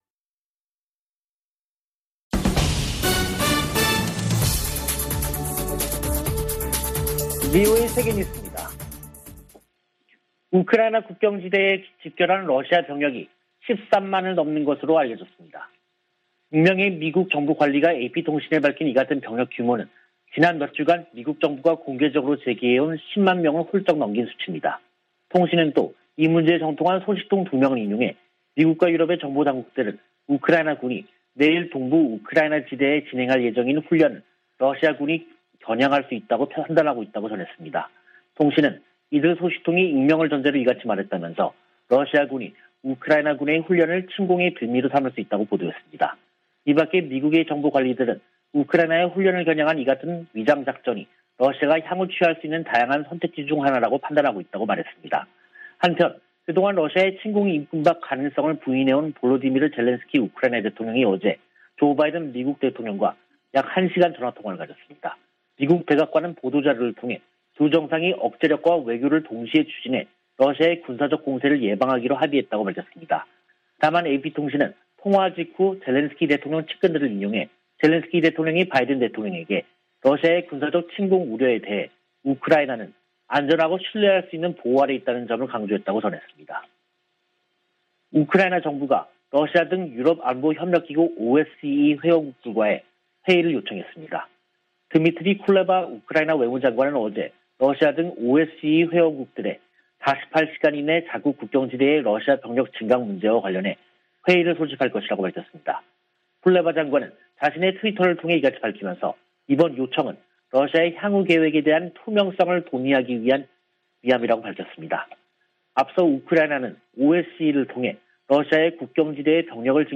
VOA 한국어 간판 뉴스 프로그램 '뉴스 투데이', 2022년 2월 14일 3부 방송입니다. 토니 블링컨 미 국무장관은 미-한-일 외교장관 회담에서 북한의 도발에 책임을 물릴 것이라고 언급했습니다. 3국 외교장관들은 공동성명에서 북한의 미사일 도발을 규탄하면서도 외교적 해법을 강조하는 기존 원칙을 확인했습니다. 1년 넘게 공석이던 주한 미국대사에 필립 골드버그 주 콜롬비아 대사가 지명됐습니다.